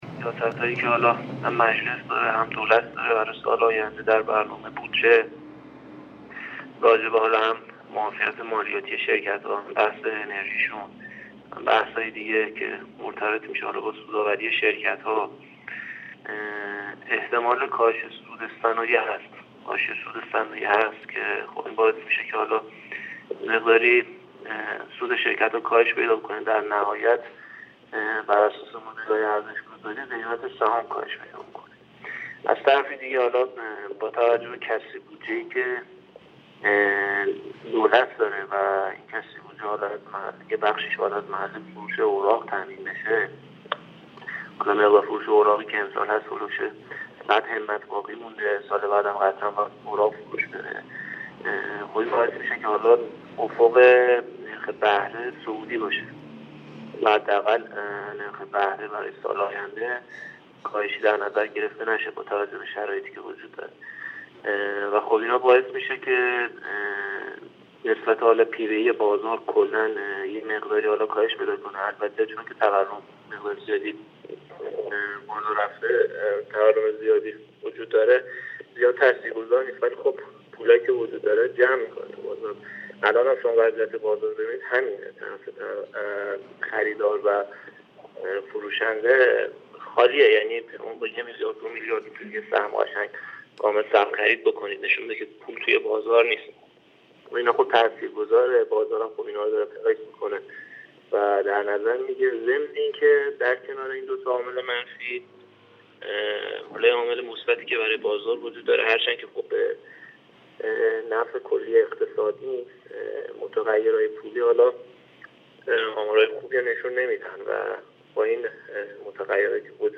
در گفت‌وگو با بورس نیوز